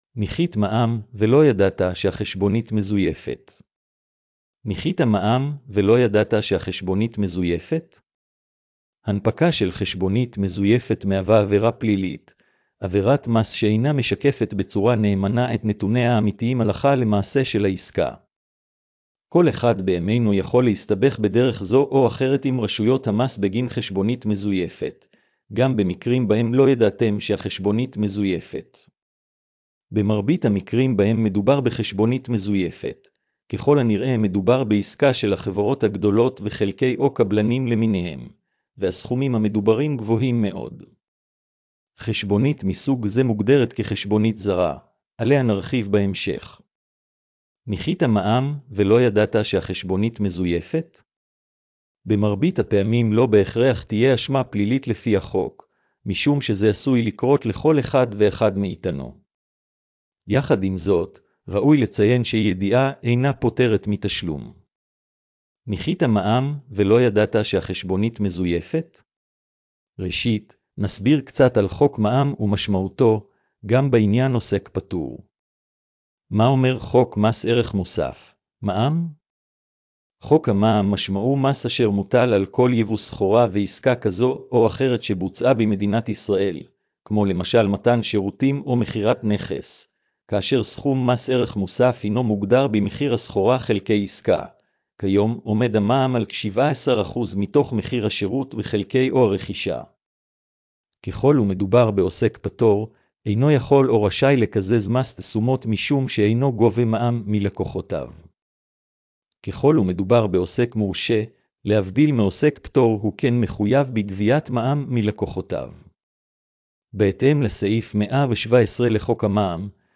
השמעת המאמר לכבדי ראייה: